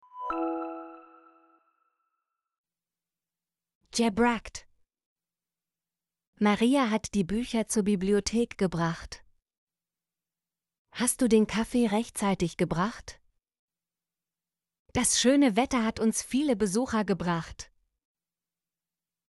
gebracht - Example Sentences & Pronunciation, German Frequency List